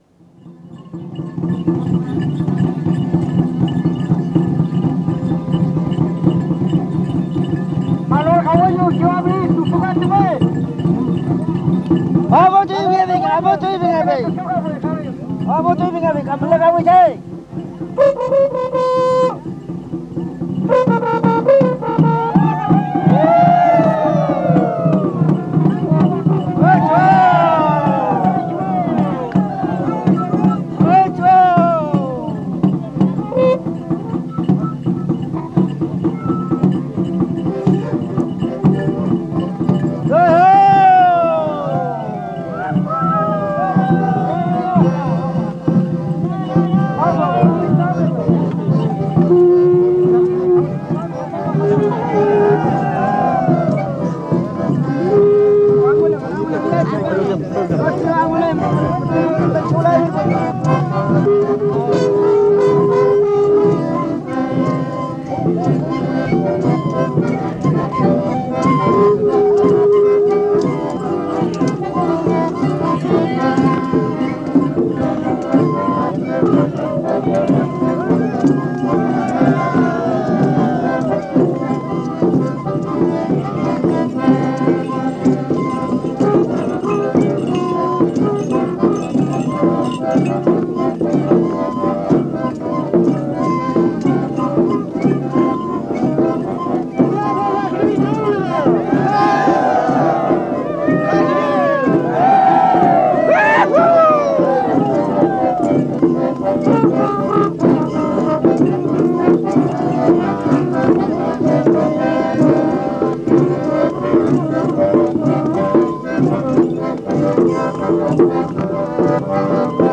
Música mapuche (Comunidad de Collinque, Lumaco)
Música tradicional